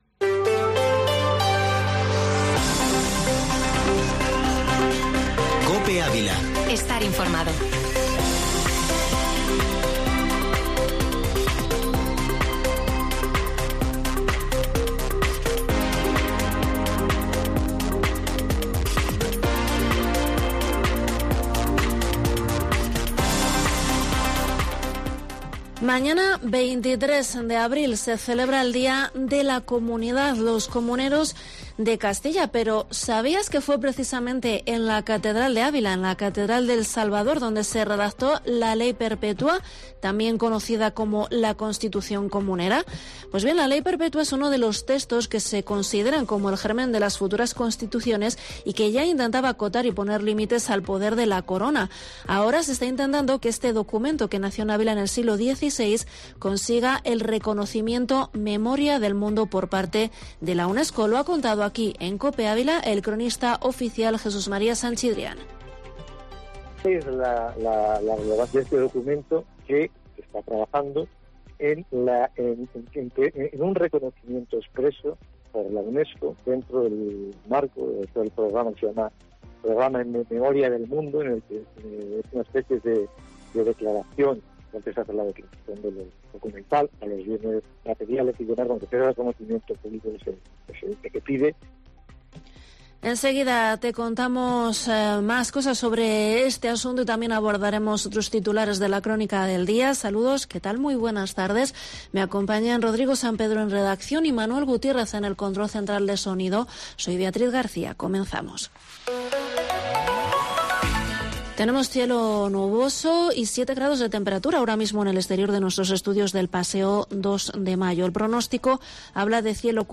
Informativo Mediodía COPE en ÁVILA 22/4/22